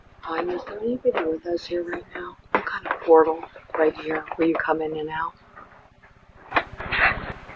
All of the investigations were conducted between 12 midnight and 3am over a two day period.
EVP's